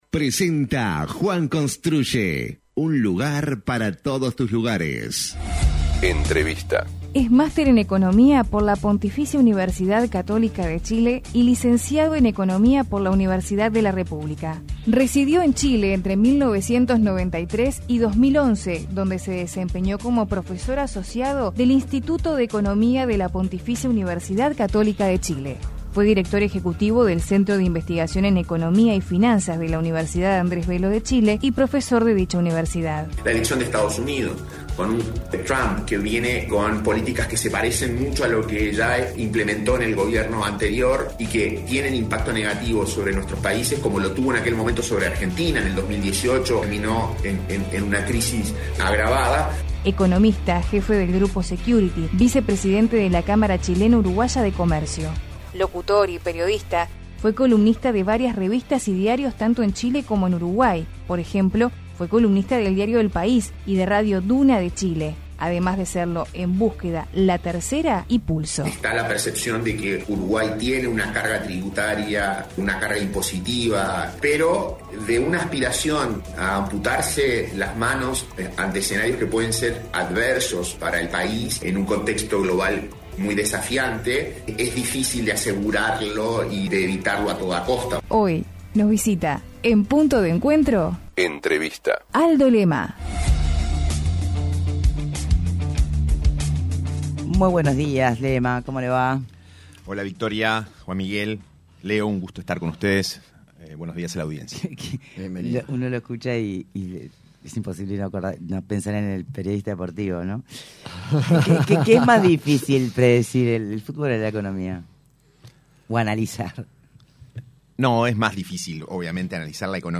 Entrevista completa aquí El economista